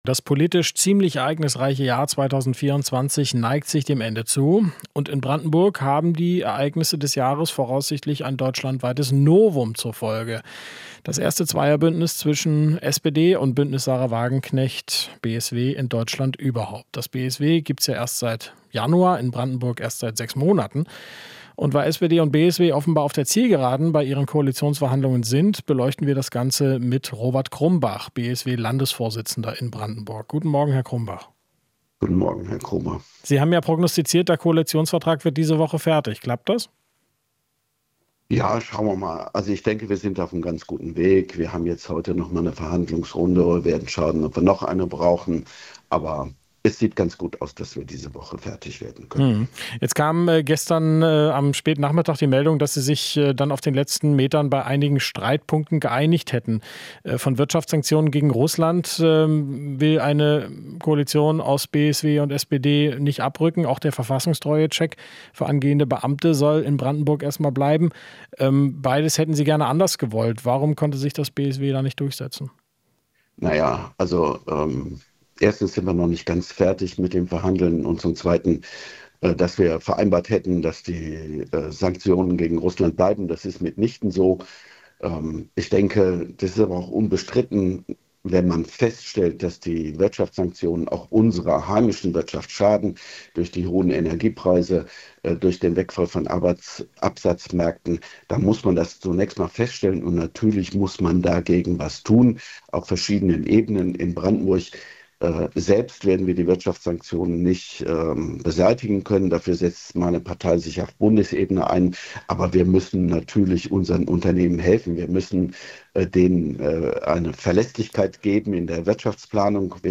Interview - Crumbach (BSW): SPD und BSW vor Einigung in Brandenburg